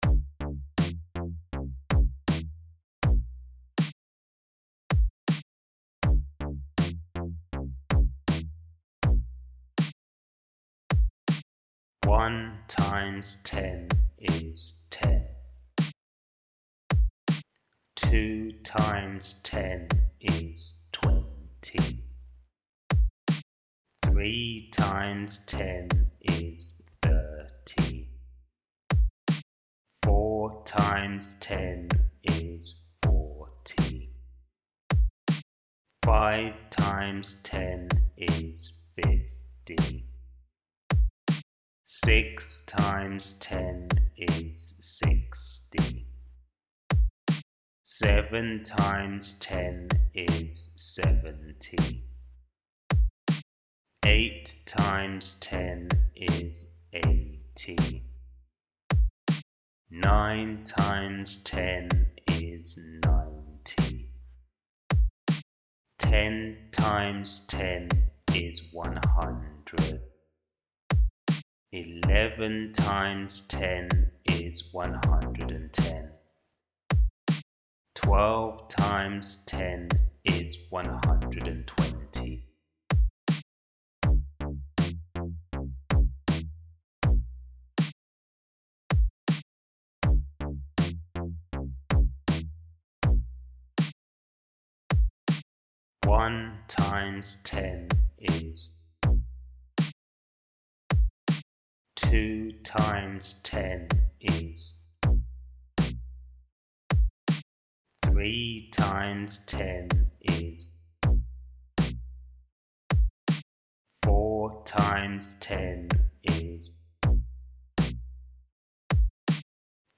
answer in time with the music